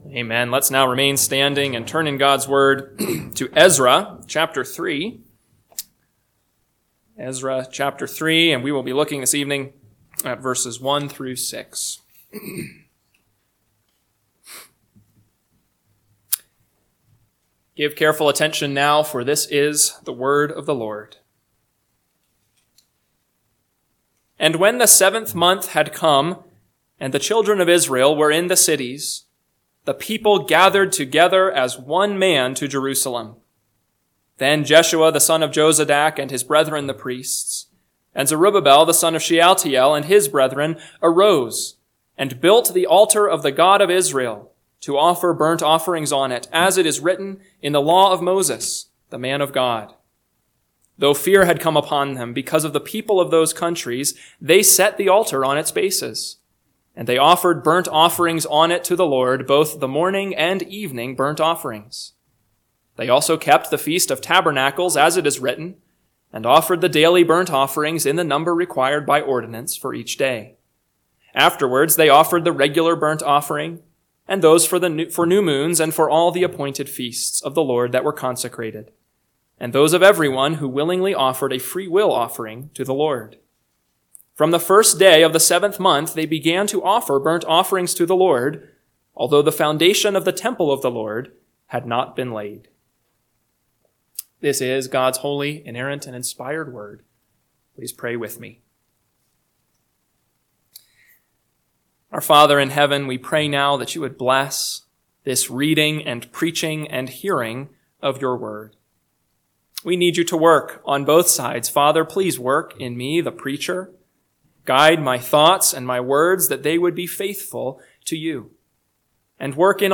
PM Sermon – 3/2/2025 – Ezra 3:1-6 – Northwoods Sermons